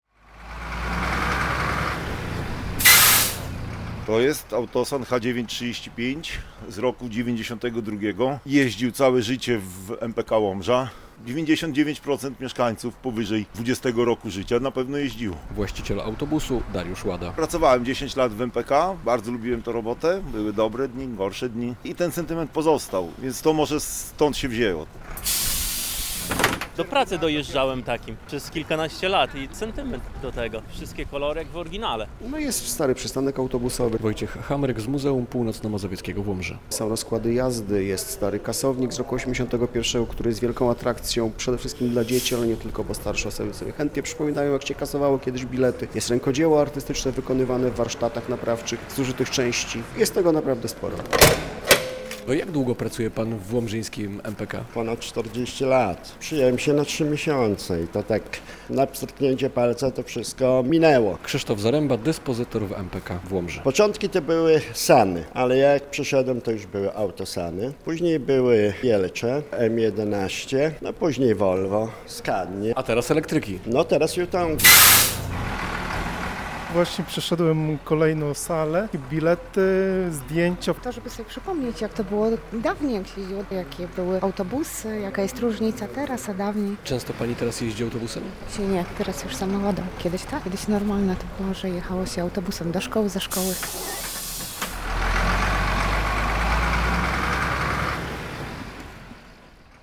55 lat MPK w Łomży - relacja